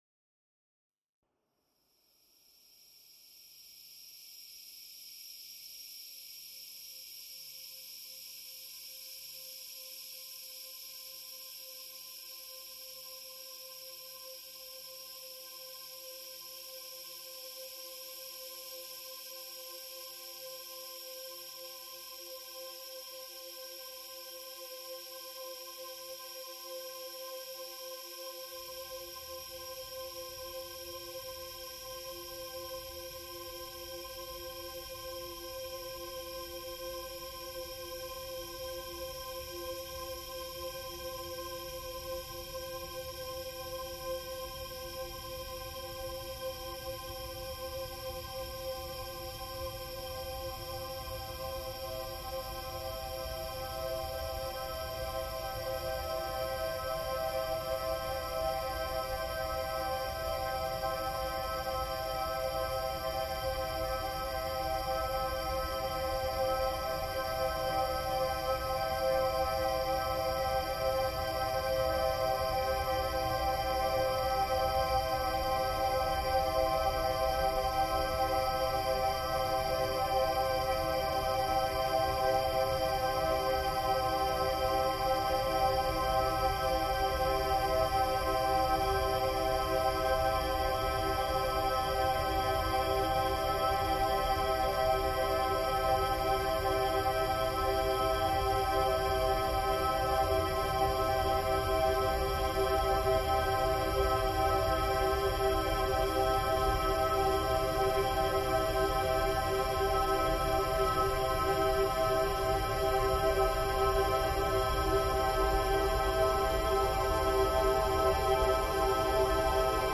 Angkor Wat temple music reimagined